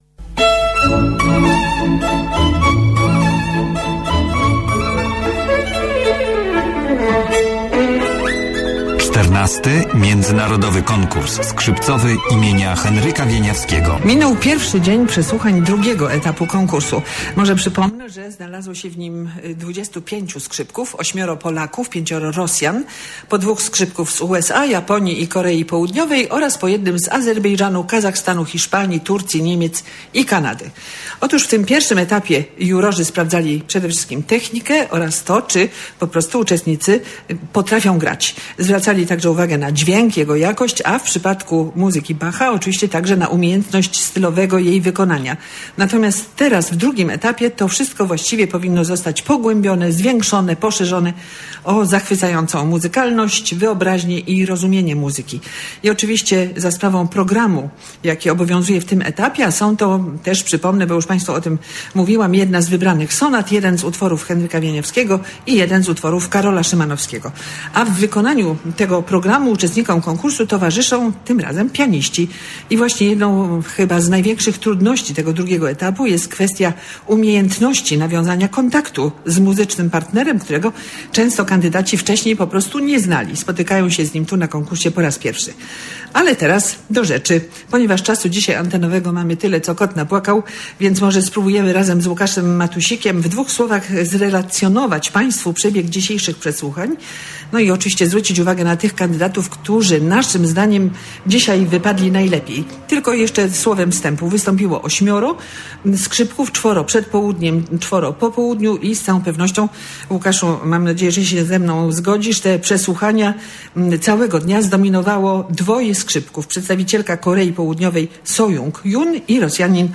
Skrzypce, brzmiące do tej pory (czyli w I etapie) solo, brzmią w Auli Uniwersyteckiej w towarzystwie fortepianu.